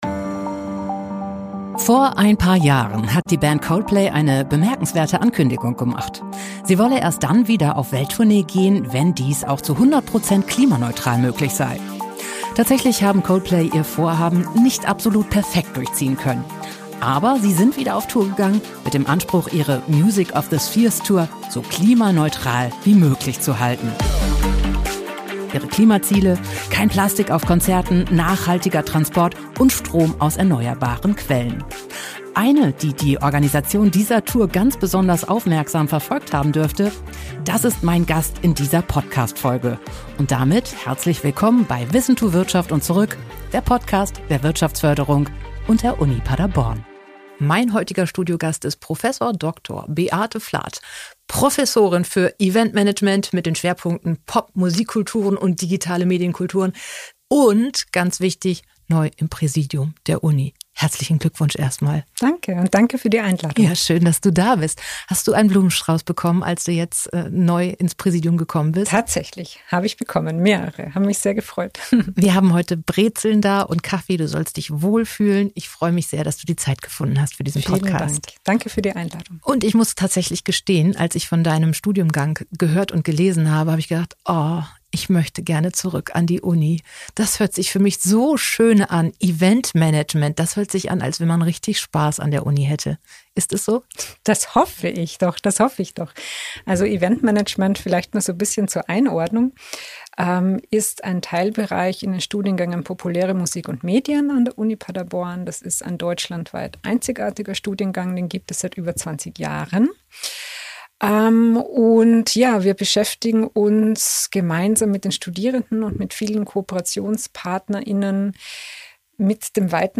Ein Gespräch über einzigartige Studiengänge, kulturelle Praxis und einen wachsenden Standort.